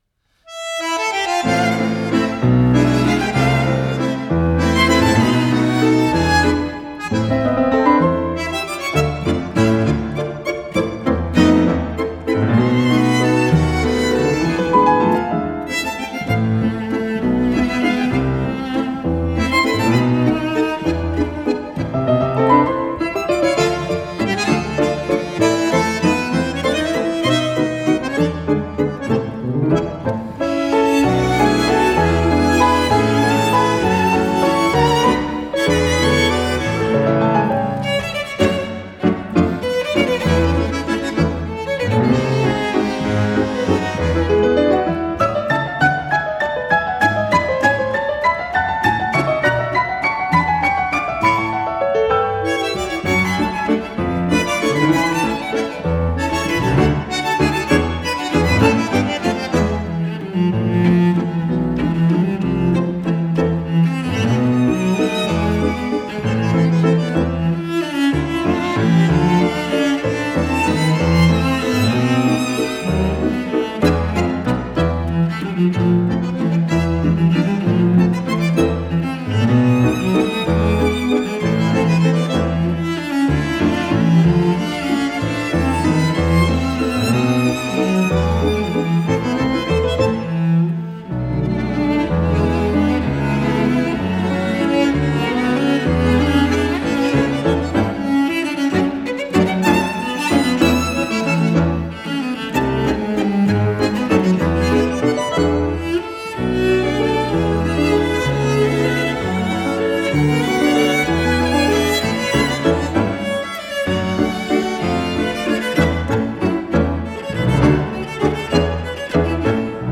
chamber-sized ensemble.
Genre: Tango